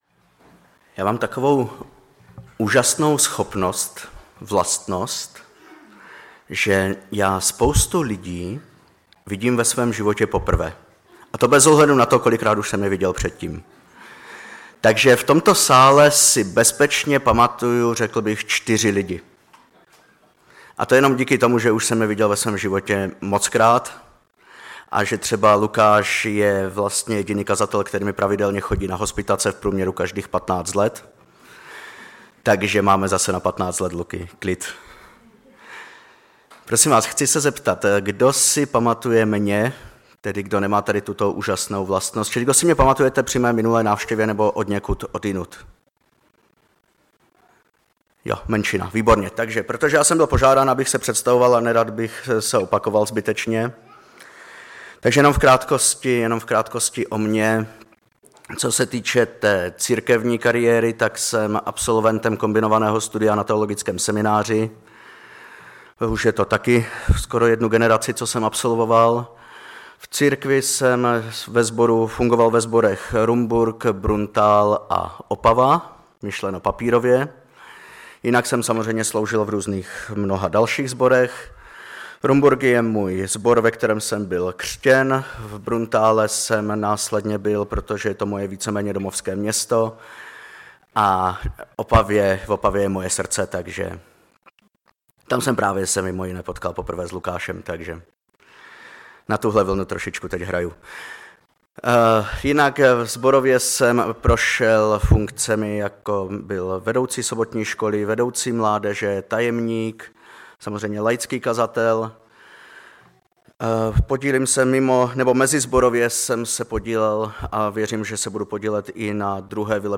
Kázání